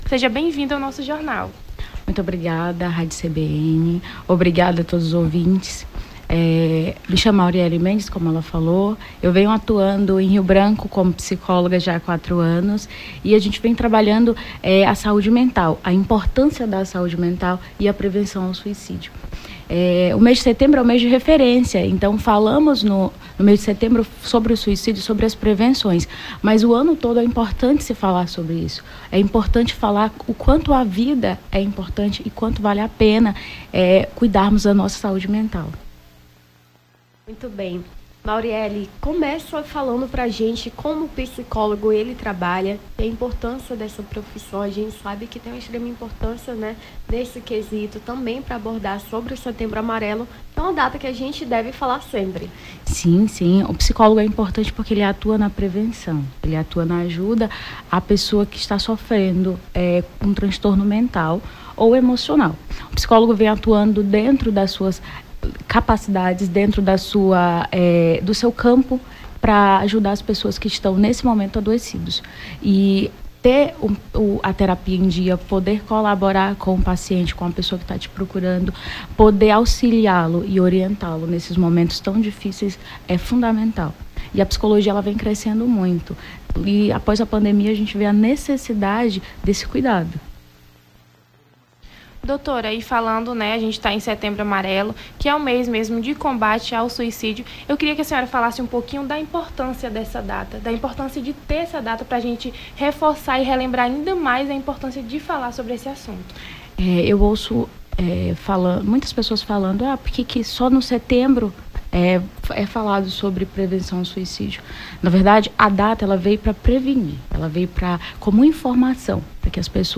Nome do Artista - CENSURA - ENTREVISTA (BOM DIA DOUTOR) 14-09-23.mp3